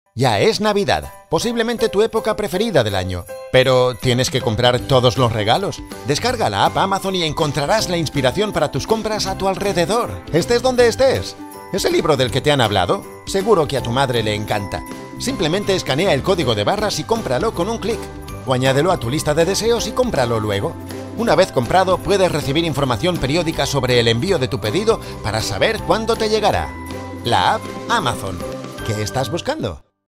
kastilisch
Sprechprobe: Werbung (Muttersprache):
I have own recording studio PRO TOOLS LE 8 and availability of travel to other studies in the area.